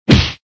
SPunch.ogg